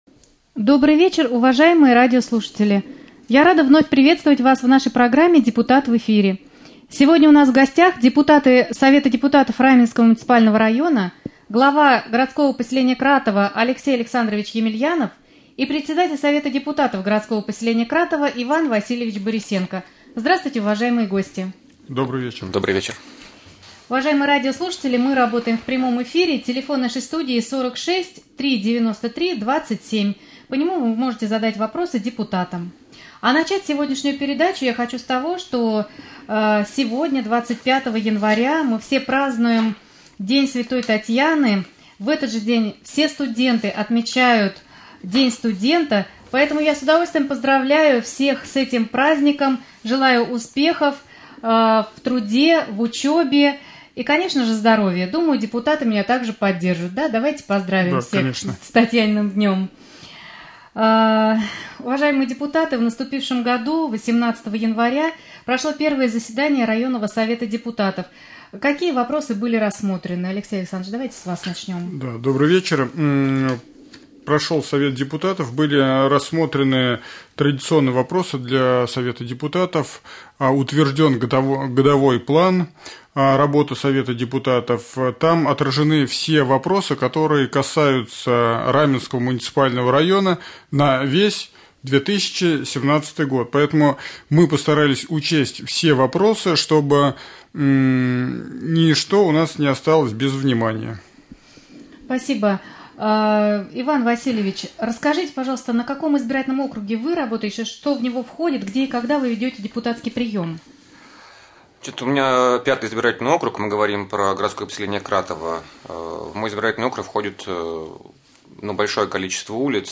Рубрика «Депутат в эфире». В прямом эфире депутаты Совета депутатов Раменского муниципального района: глава г.п.Кратово Алексей Александрович Емельянов и председатель Совета депутатов г.п.Кратово Иван Васильевич Борисенко.